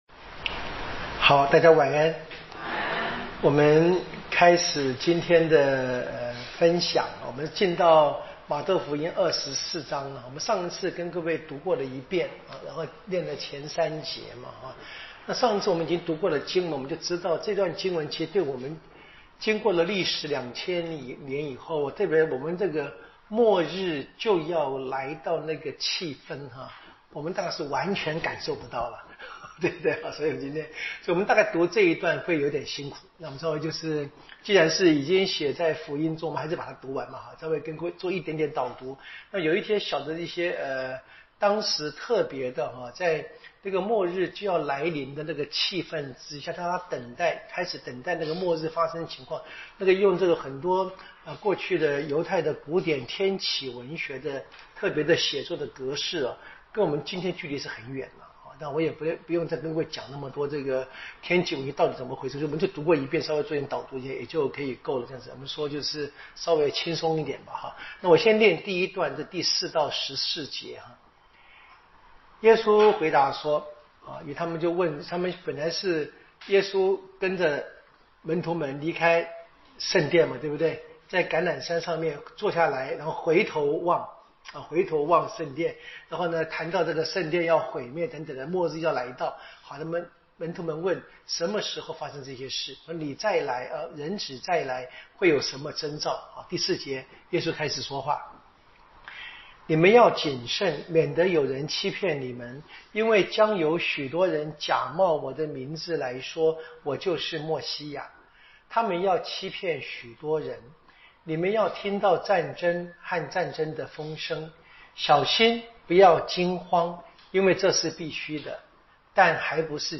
【圣经讲座】《玛窦福音》